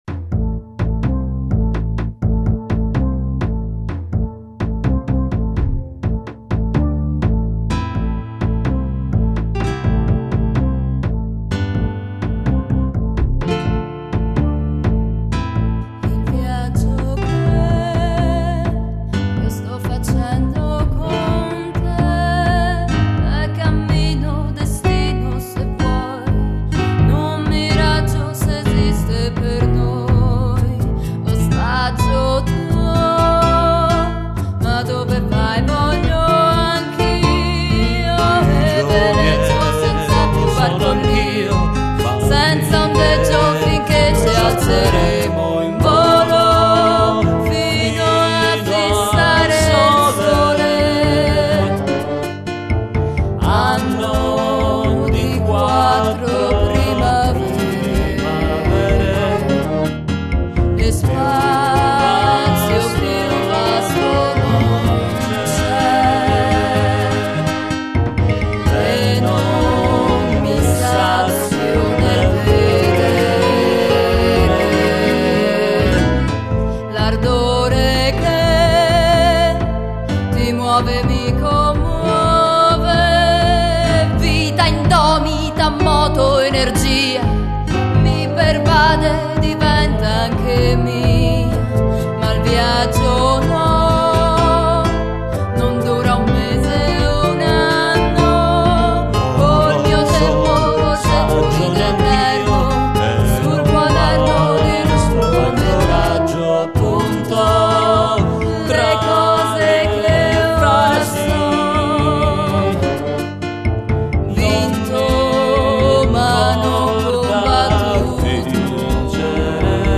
spettacolo musicale tragicomico (come tutto è)